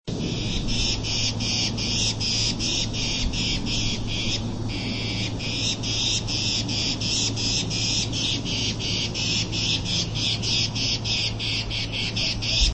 16-3溪頭藪鳥alarm1.mp3
黃痣藪鶥 Liocichla steerii
錄音地點 南投縣 鹿谷鄉 溪頭
錄音環境 森林
警戒叫聲